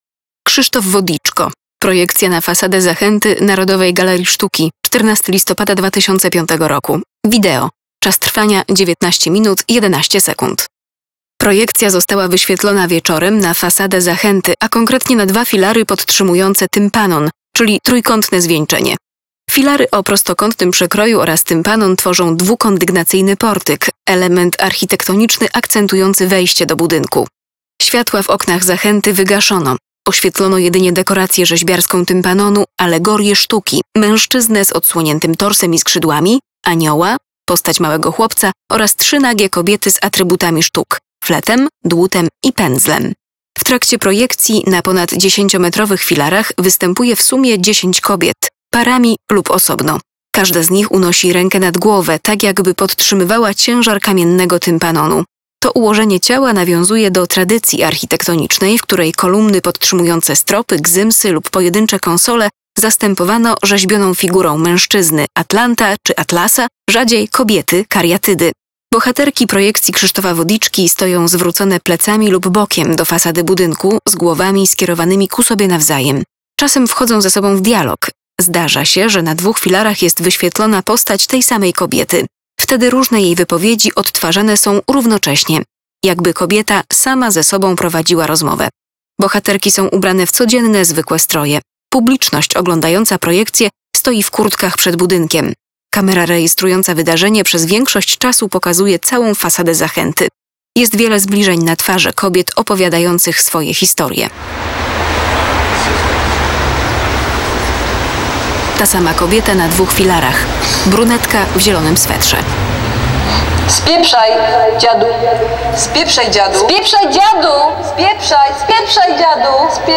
Audiodeskrypcja: Krzysztof Wodiczko, Projekcja na fasadę Zachęty - Mediateka - Zachęta Narodowa Galeria Sztuki